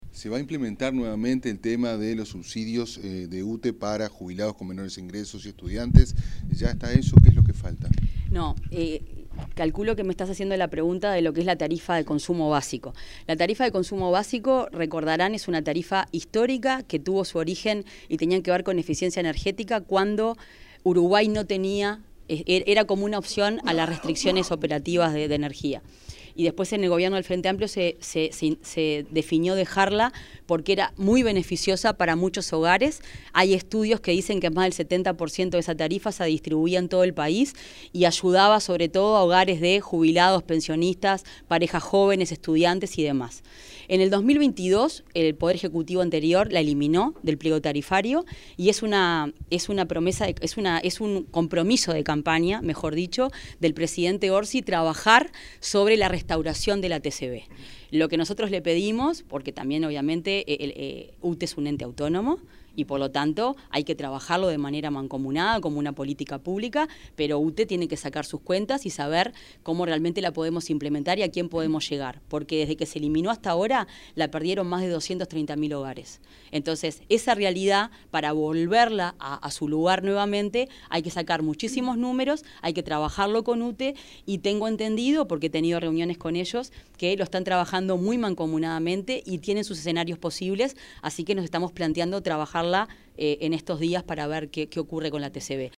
Declaraciones de la ministra de Industria, Fernanda Cardona